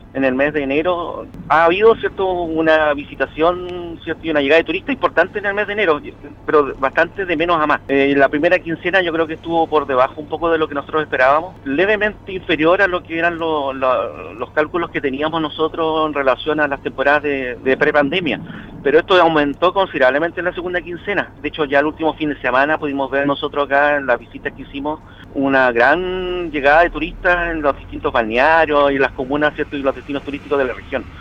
En conversación con Radio Sago, el director (s) del Servicio Nacional de Turismo de la región de Los Lagos, Luis Hurtado, entregó su percepción respecto al primer mes del año, realizando una evaluación del rubro turístico. En la instancia señaló que se ha evidenciado un alza de visitantes con el paso de las semanas.